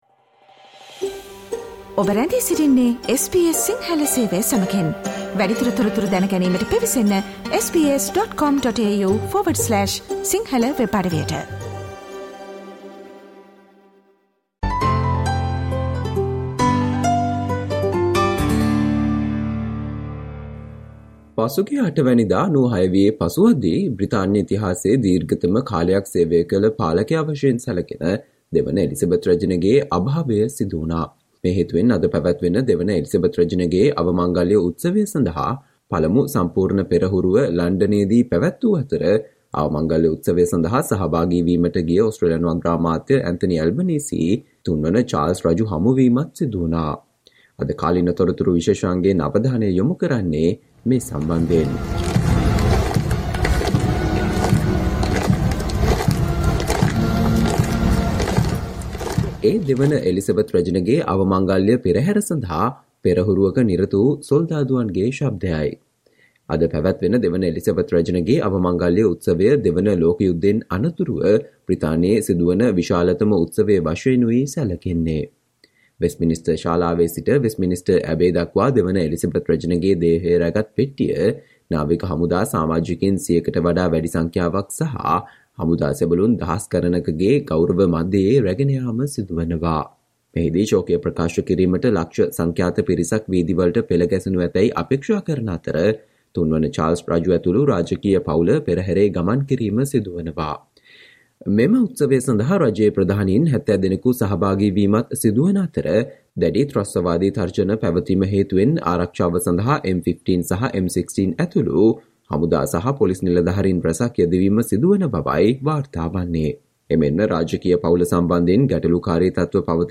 Today - 19 September, SBS Sinhala Radio current Affair Feature on Queen Elizabeth's state funeral is today